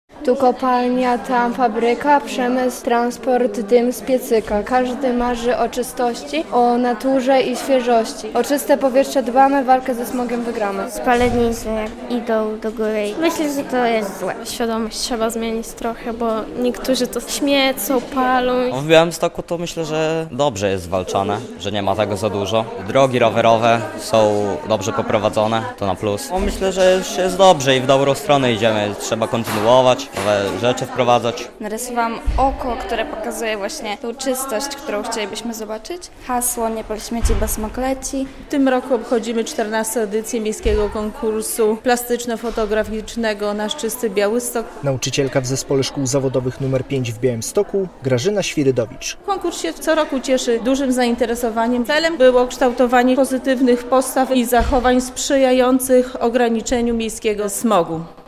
Radio Białystok | Wiadomości | Wiadomości - Dzieci i młodzież pokazują, jak chronić środowisko